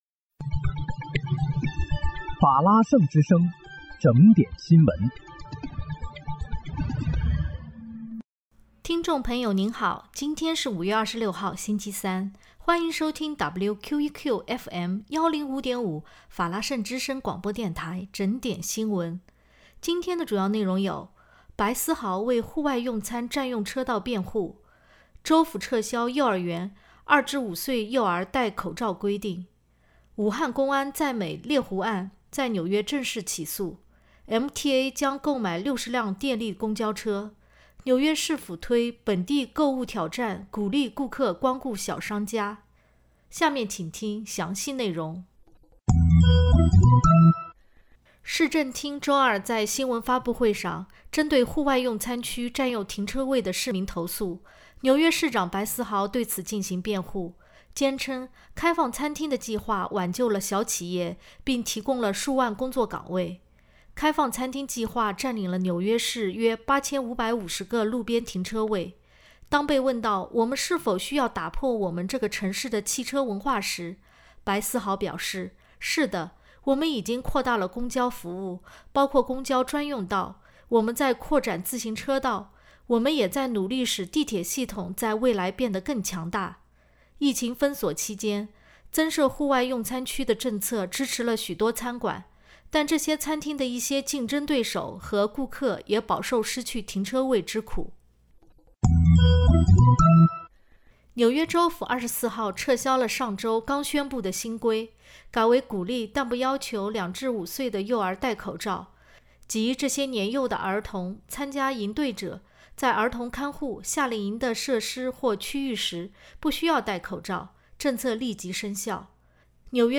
5月26日（星期三）纽约整点新闻